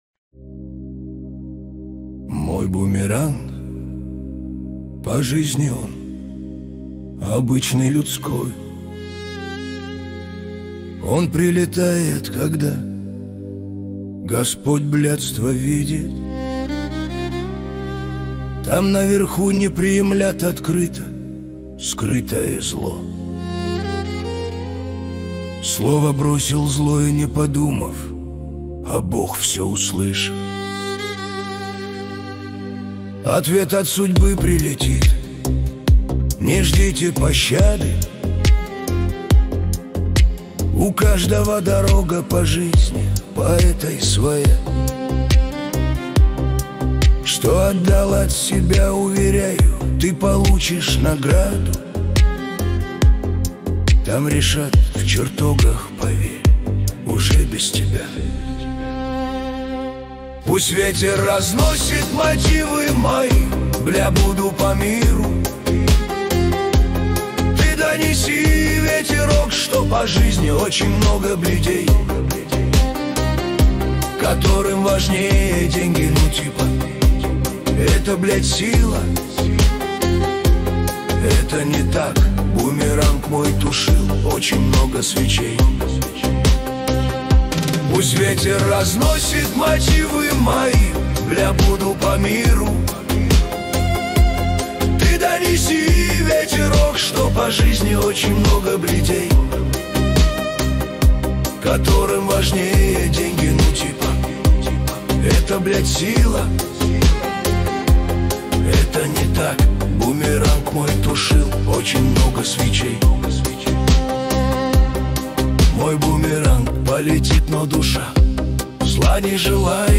13 декабрь 2025 Русская AI музыка 72 прослушиваний